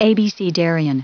Prononciation du mot abecedarian en anglais (fichier audio)
Prononciation du mot : abecedarian